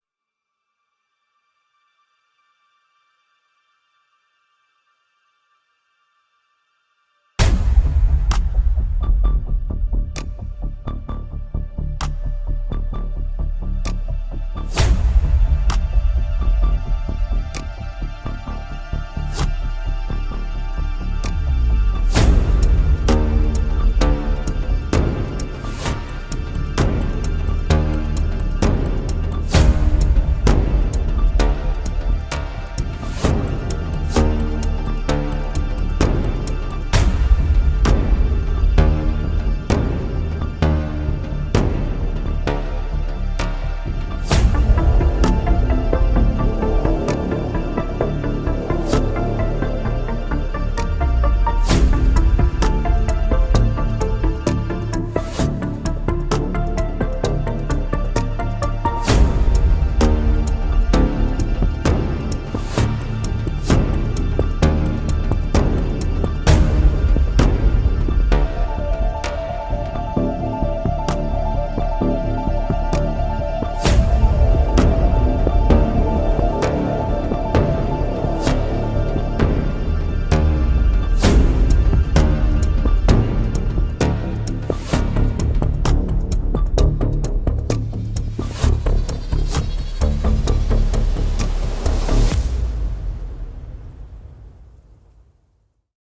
tema dizi müziği, heyecan gerilim aksiyon fon müziği.